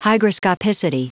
Prononciation du mot hygroscopicity en anglais (fichier audio)
Prononciation du mot : hygroscopicity